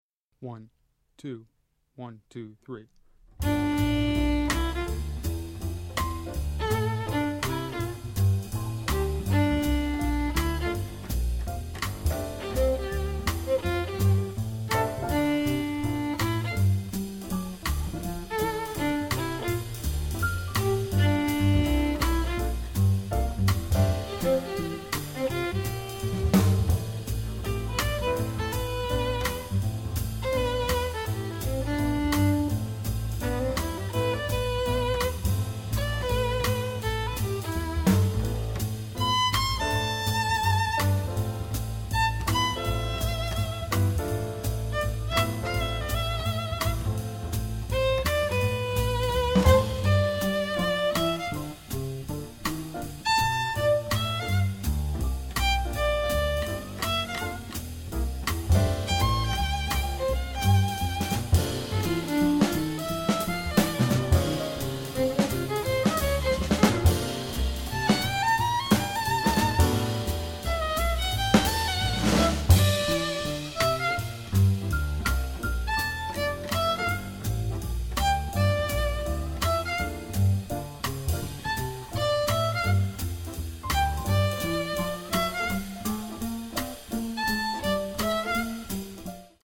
Voicing: Jazz Violi